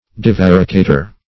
Search Result for " divaricator" : The Collaborative International Dictionary of English v.0.48: Divaricator \Di*var`i*ca"tor\, n. (Zool.)